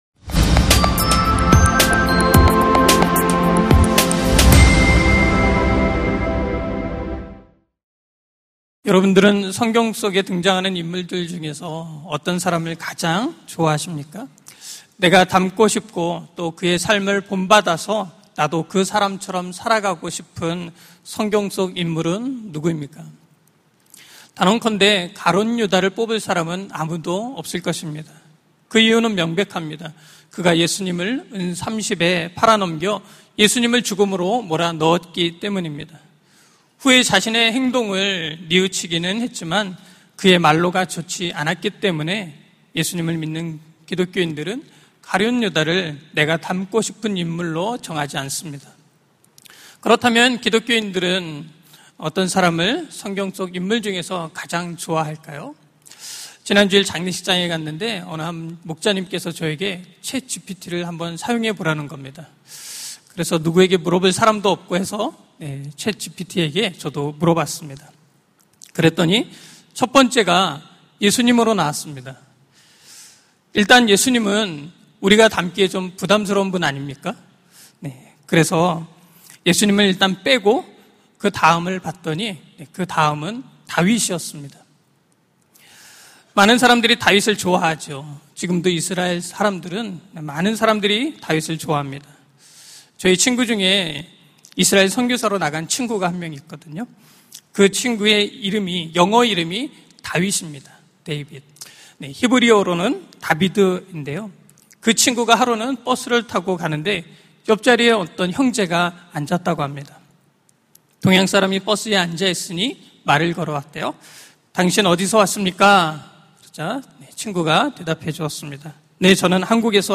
설교 : 수요향수예배 (분당채플) 닮고싶은 그 사람, 백부장! 설교본문 : 마태복음 8:5-13